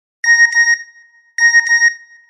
Play Nokia SMS Alert - SoundBoardGuy
Play, download and share Nokia SMS Alert original sound button!!!!
nokia-sms-alert.mp3